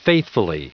Prononciation du mot faithfully en anglais (fichier audio)
Prononciation du mot : faithfully